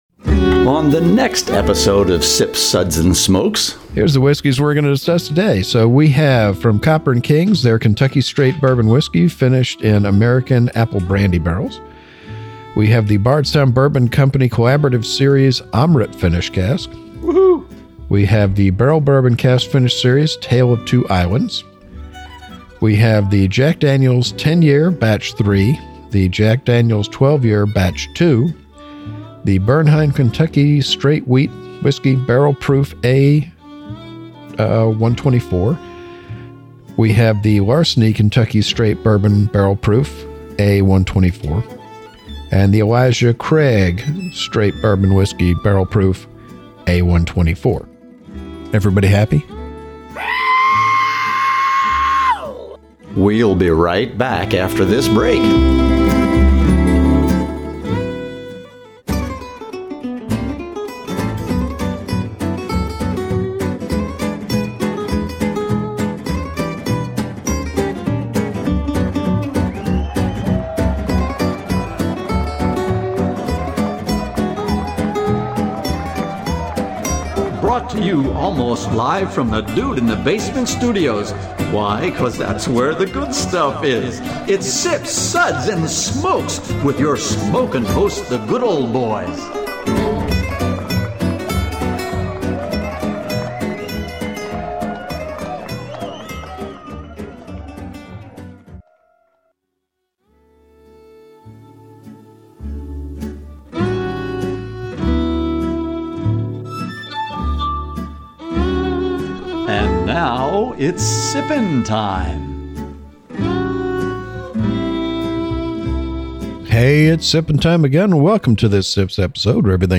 Whether you're a seasoned whiskey lover or a curious newcomer, this episode is packed with tasting notes, laughs, and a few surprises. Embark on a spirited journey through a diverse lineup of exquisite bourbons and whiskeys.